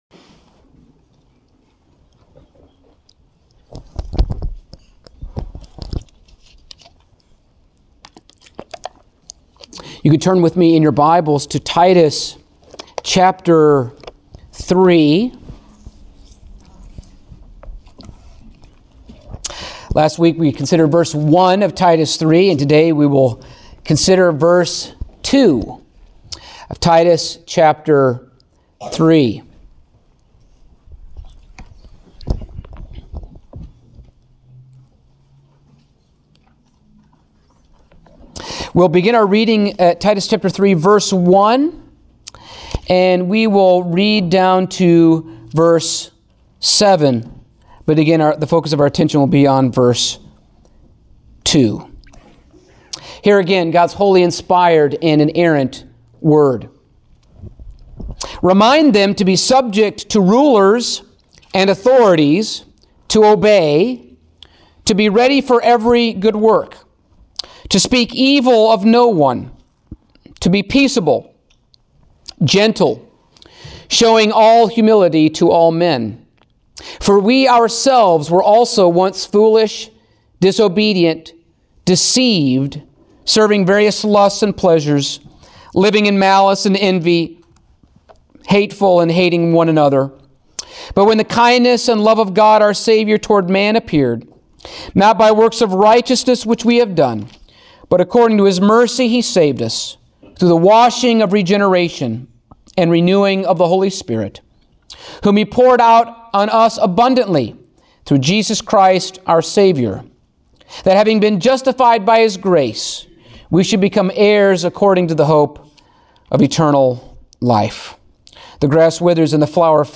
Passage: Titus 3:2 Service Type: Sunday Morning